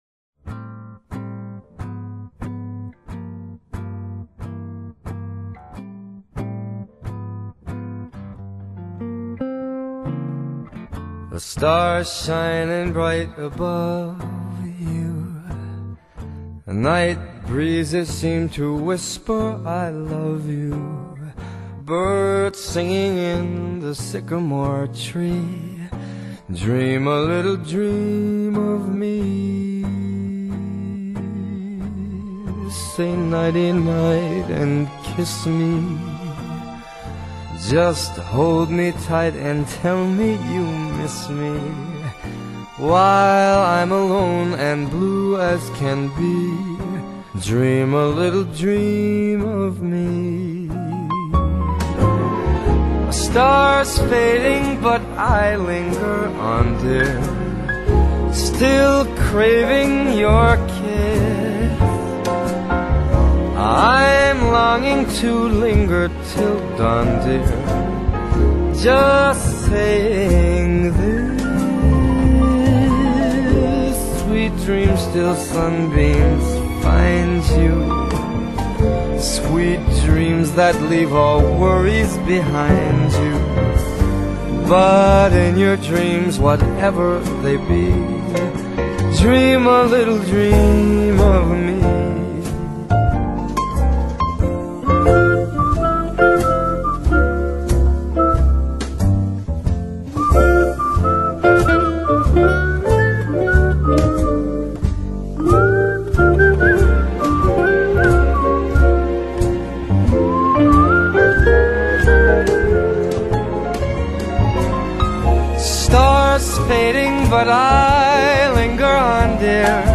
音乐类型：爵士乐
管号乐铺排与华丽编曲